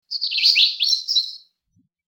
Birds Chirping #2 | TLIU Studios
Category: Animal Mood: Calming Editor's Choice